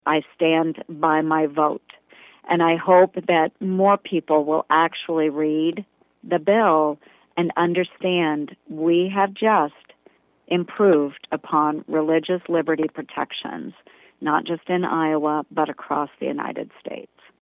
During a conference call with Iowa reporters, Ernst said the bill codified exemptions for religious institutions that do not wish to recognize same-sex unions.